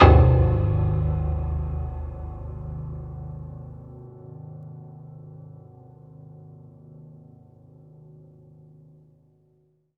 PIANO BASS.wav